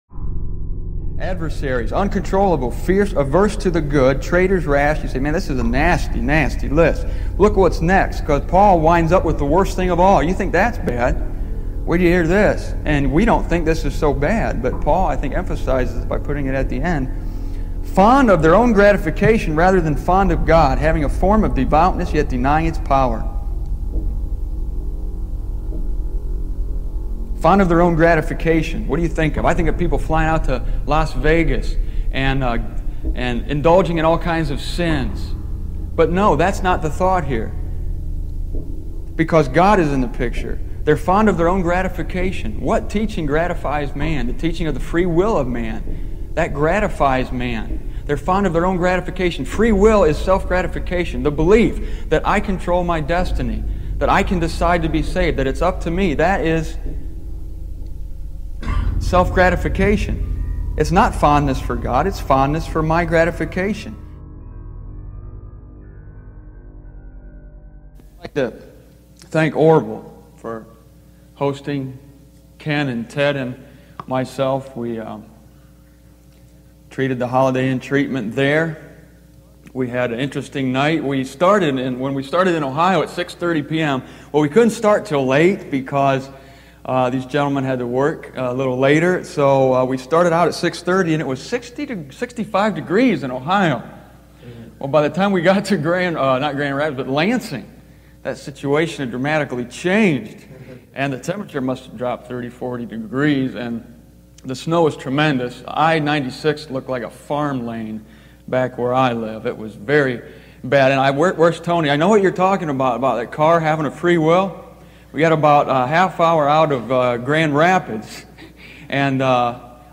In 1995 when this audio was recorded (in Grand Rapids, MI), I did not yet understand what is obvious to me now, that those who hold to tradition rather than truth are, despite their confession, unbelievers.